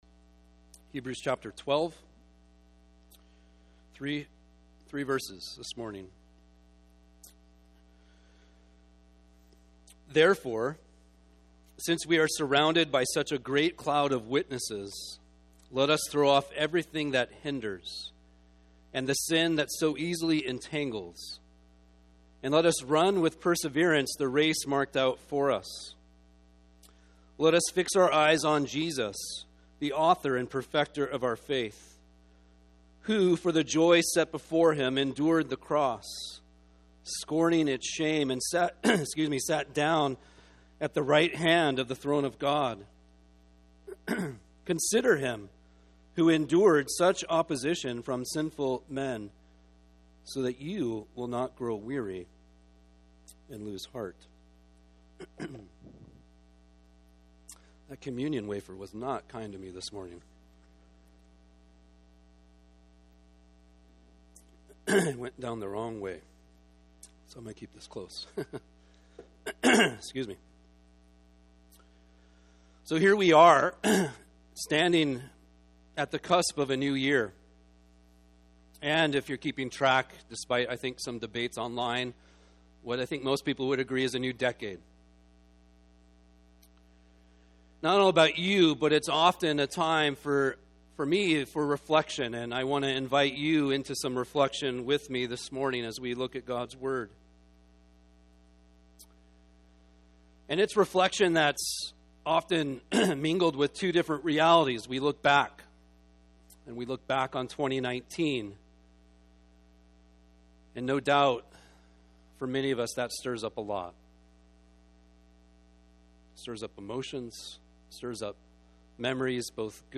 MESSAGES | Maple Ridge Christian Reformed Church
Sermon